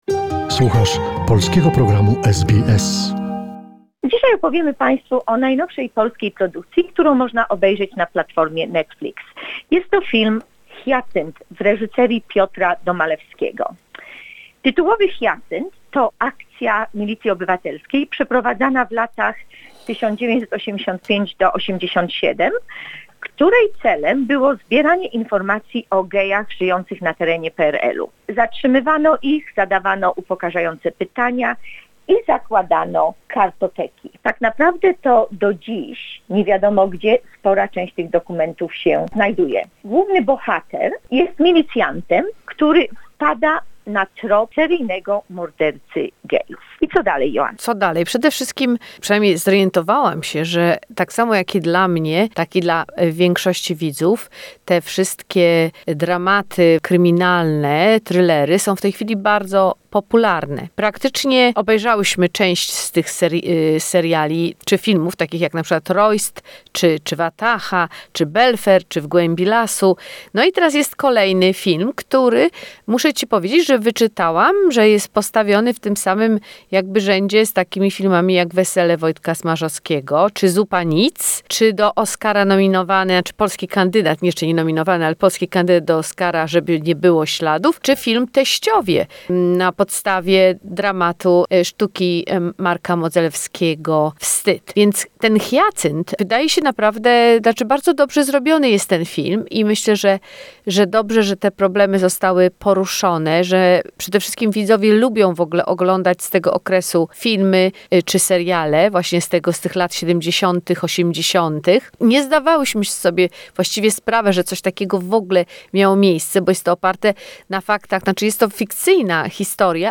Recenzja filmowa "Hiacynt"